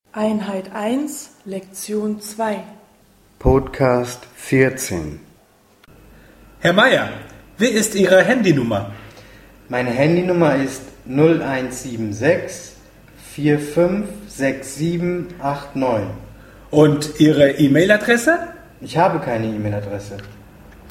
Dialog 3